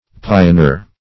pioner - definition of pioner - synonyms, pronunciation, spelling from Free Dictionary Search Result for " pioner" : The Collaborative International Dictionary of English v.0.48: Pioner \Pi`o*ner"\, n. A pioneer.